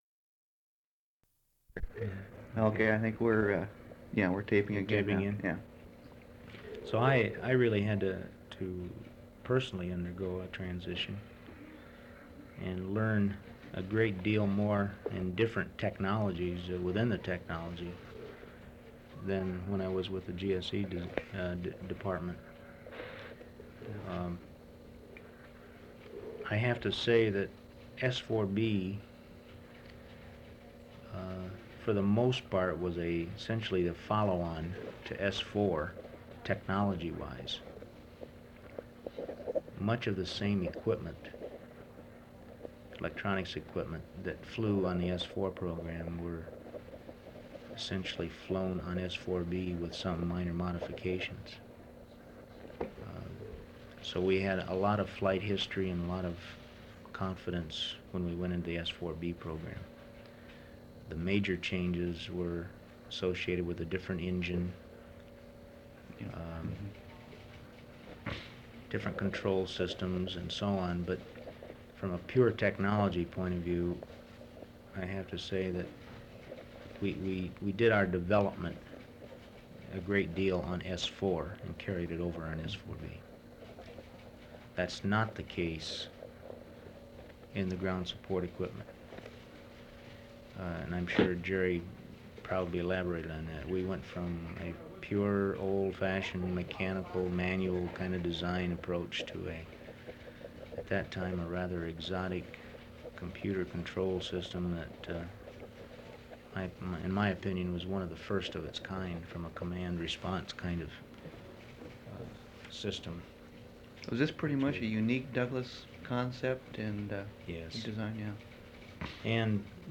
Type Interviews
Relation clir_grant_audio_metadata Oral History Item Type Metadata Duration 1:03:07 Collection Saturn V Collection Tags Oral History Citation United States.